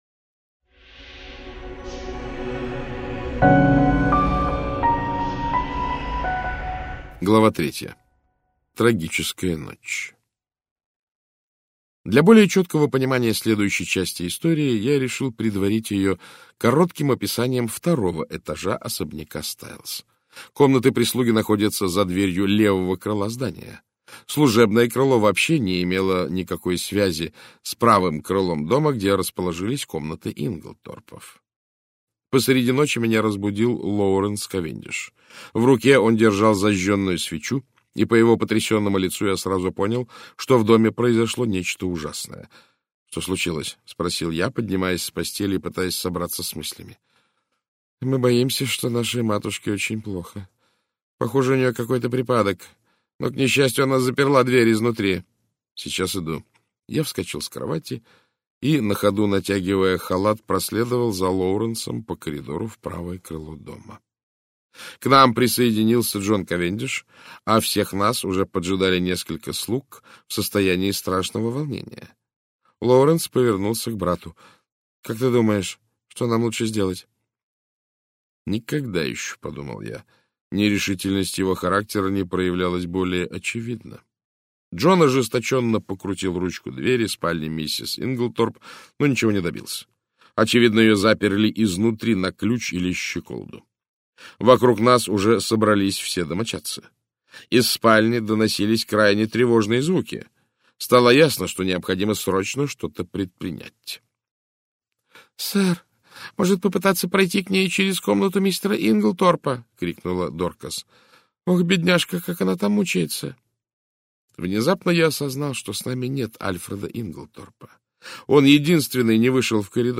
Аудиокнига Загадочное происшествие в Стайлзе - купить, скачать и слушать онлайн | КнигоПоиск